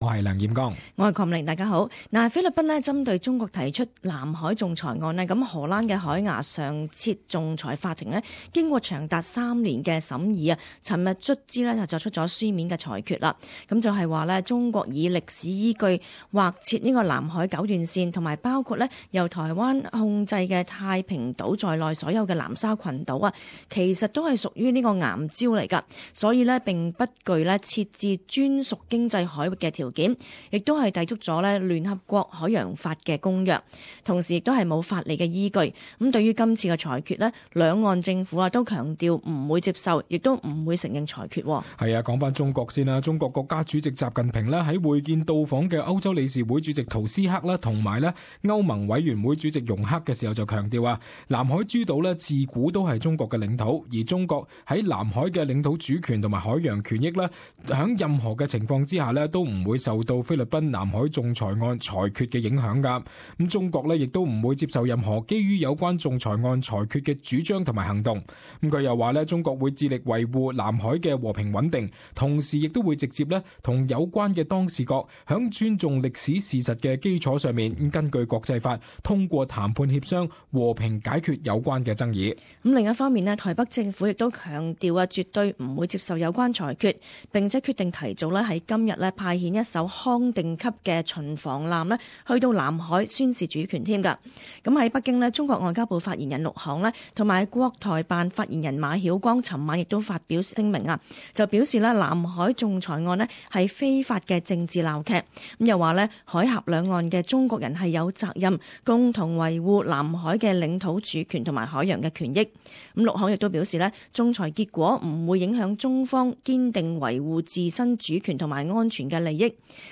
【時事報道】海牙裁決指九段線抵觸海洋法公約